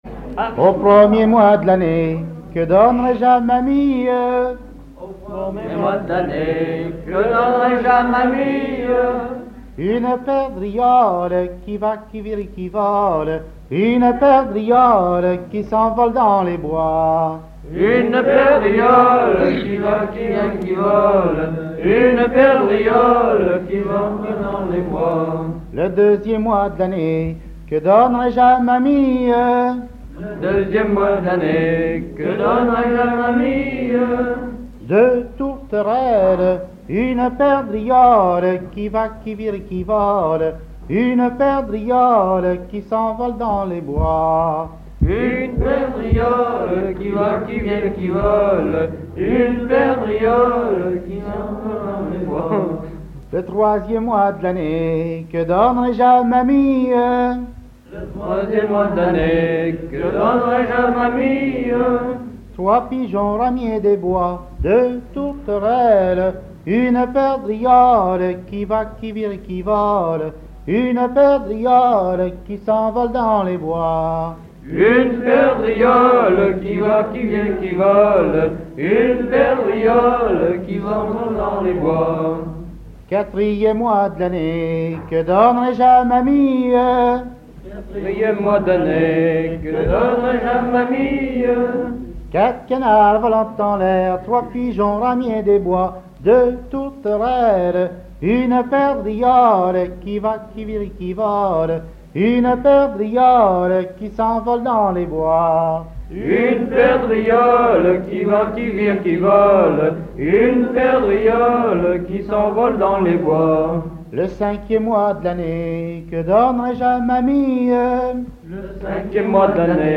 Genre énumérative
à la salle d'Orouët
Pièce musicale inédite